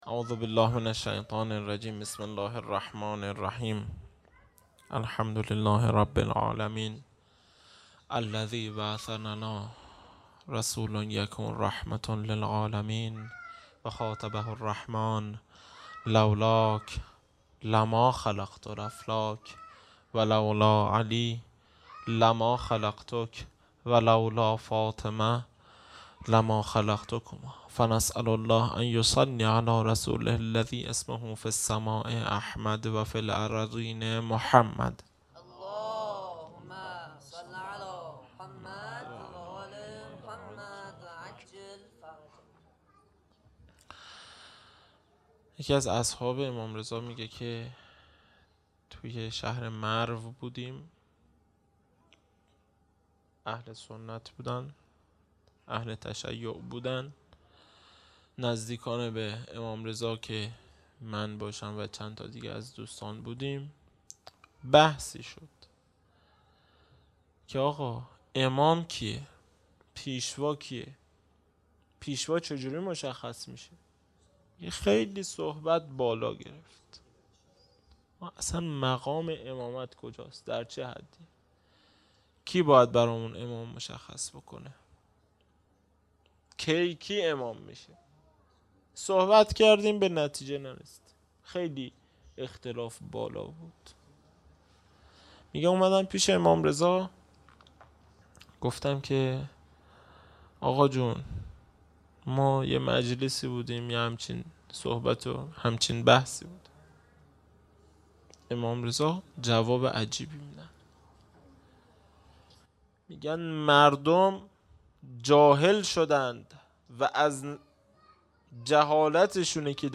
خیمه گاه - هیئت بچه های فاطمه (س) - سخنرانی
جلسۀ هفتگی 23 خرداد 1398 (به مناسبت تخریب قبور ائمه بقیع(ع))